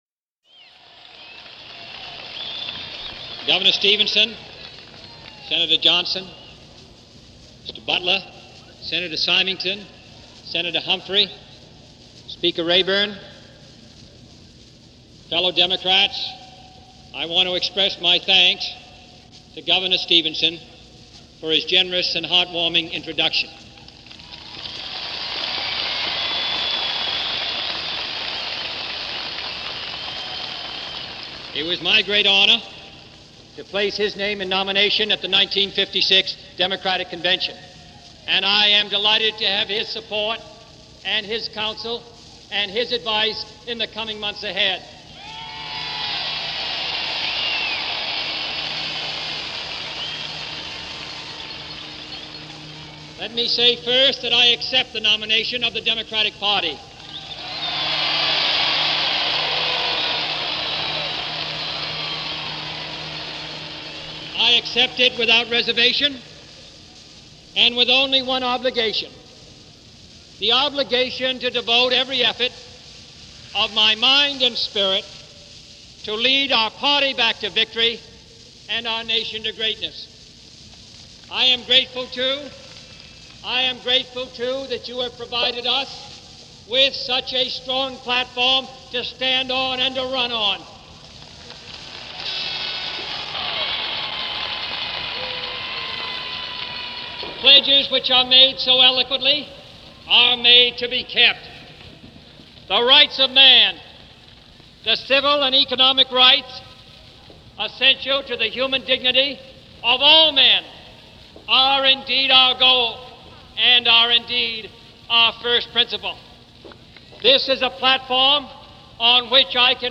John F. Kennedy accepts the nomination of the Democratic Party for President in 1960. Given at Los Angeles on July of 1960.
JFK-Democratic-convention-address-July-1960-revised.mp3